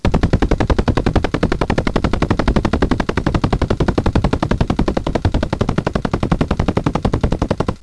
Here is a .wav file of me, shooting my backup mechanical cocker at 11bps.
Yes, I was fanning the trigger, and no, I do not shoot that way when I play.
It sounds muffled because clip on mic was mostly inside the barrel, I didn't want anything but the air coming out of the barrel recorded. Also, the file isn't looped, that's seven solid seconds of 11 bps.
cocker.wav